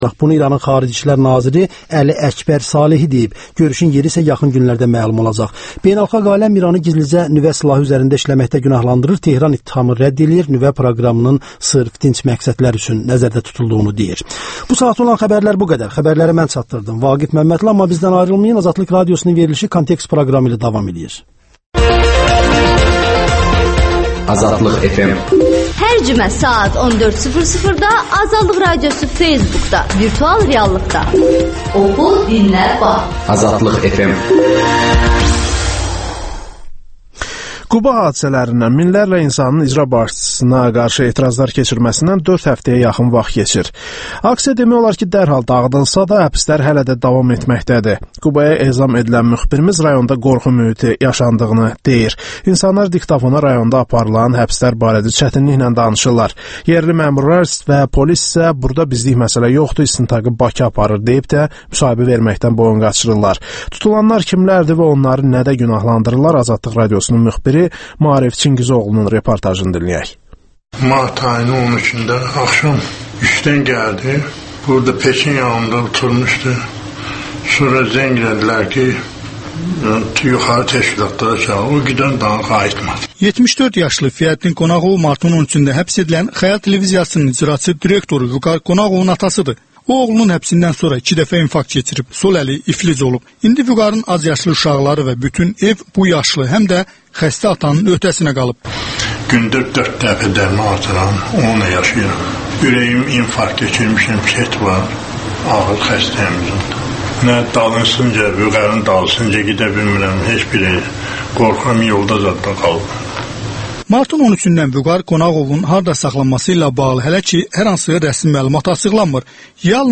Kontekst - Qubada həbslər davam edir [Müxbirimizin rayondan reportajı]
Müsahibələr, hadisələrin müzakirəsi, təhlillər